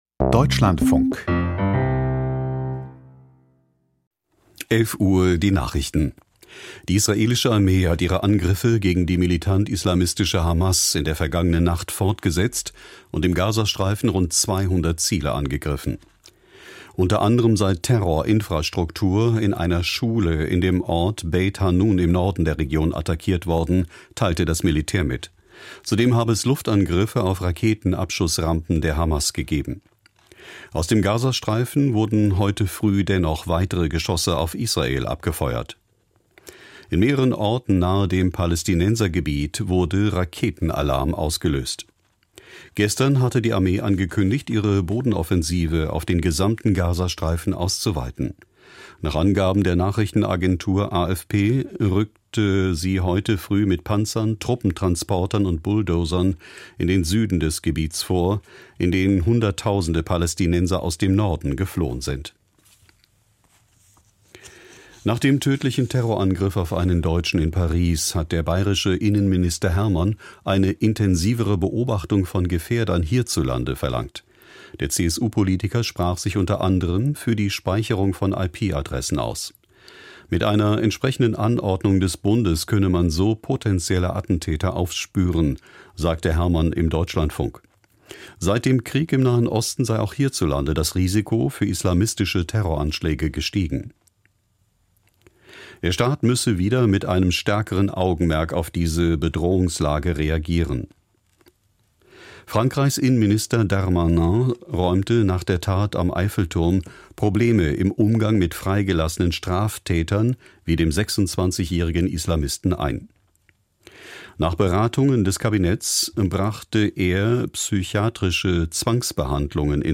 Die wichtigsten Nachrichten aus Deutschland und der Welt.